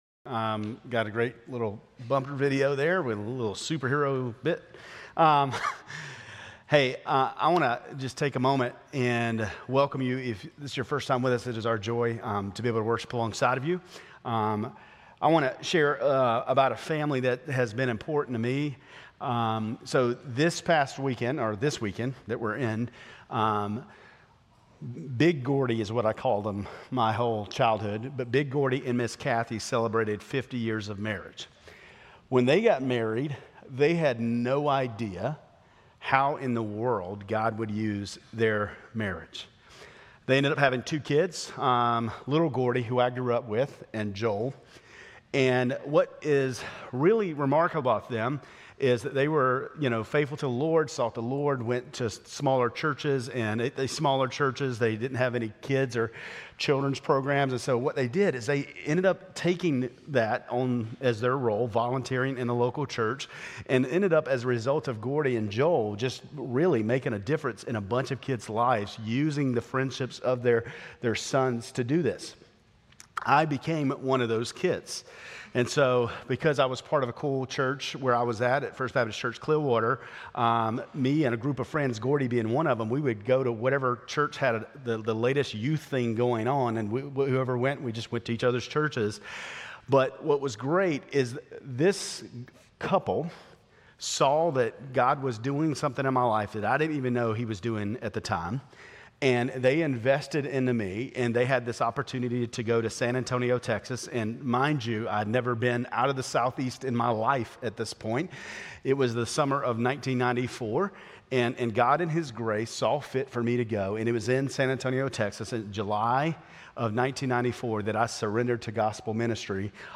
Grace Community Church Lindale Campus Sermons 6_29 Lindale Campus Jul 01 2025 | 00:25:21 1x 00:00 / 00:25:21 Subscribe Share RSS Feed Share Link Embed